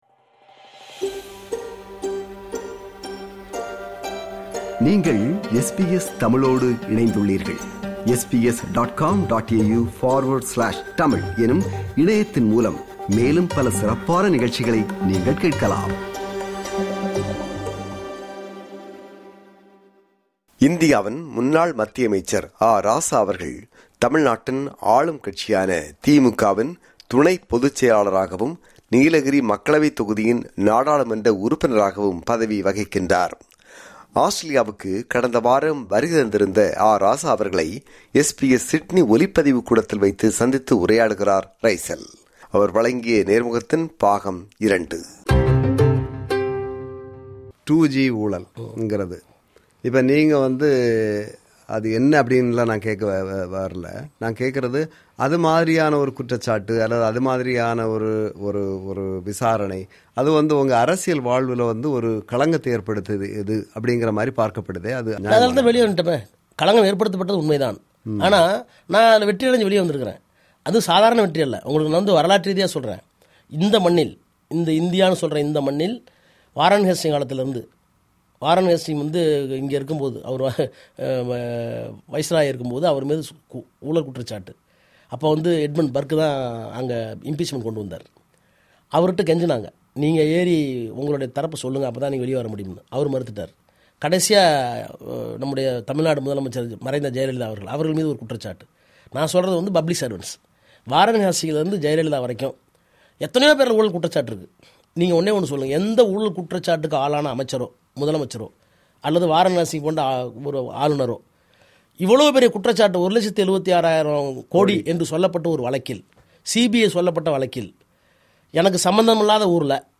நேர்முகம் பாகம் 2.